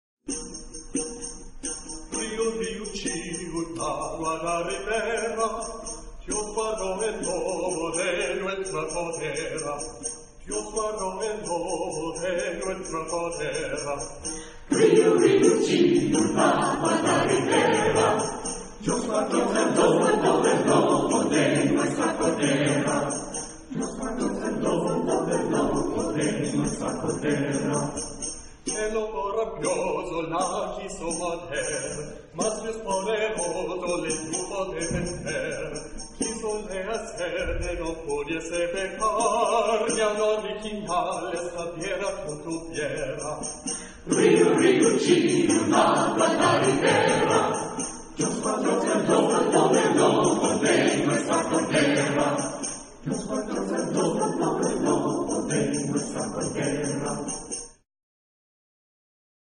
SATB (4 voices mixed) ; Full score.
Renaissance. Folk music. Dance. Secular.
sung by Susquehanna Chorale (USA)